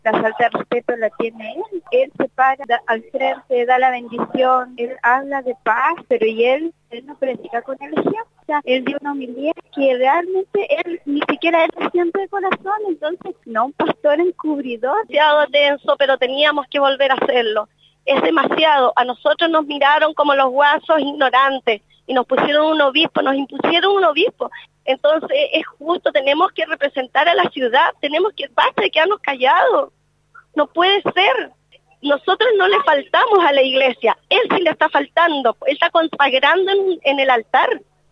Tras su salida las reacciones no se hicieron esperar, Radio Sago conversó con varios integrantes del Movimiento quienes aseguraban que seguirán hasta las últimas consecuencias con tal que renuncie el Obispo, ya que, señalan existe división católica por su persona al mando de la Diócesis de Osorno.